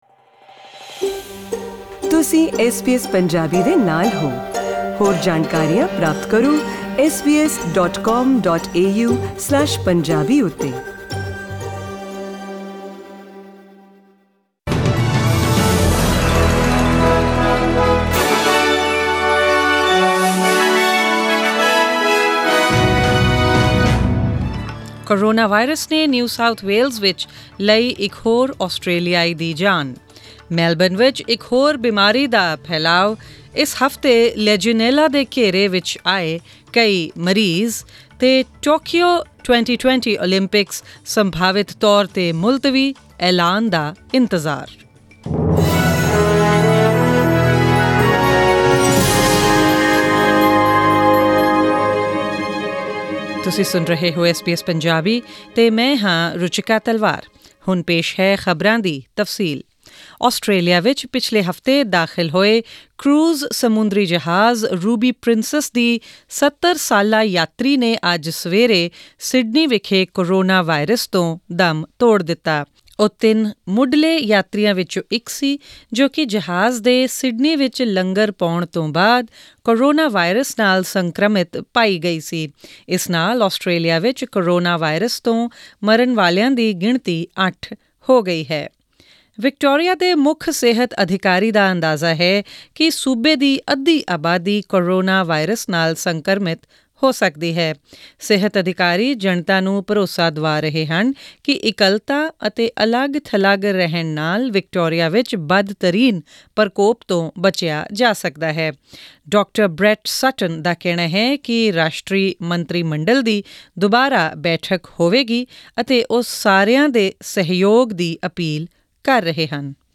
Australian News in Punjabi: 24 March 2020